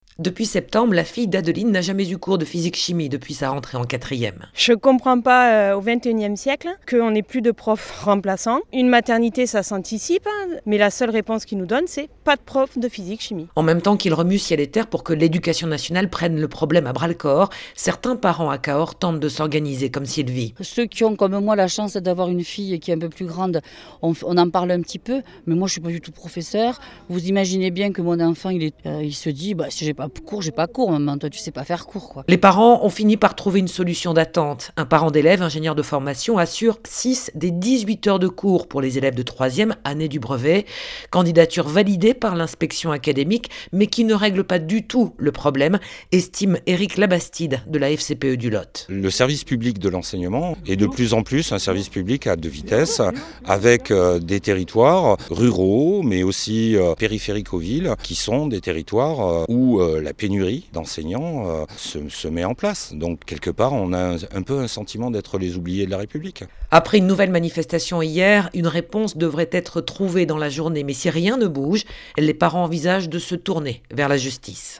Reportage Sud Radio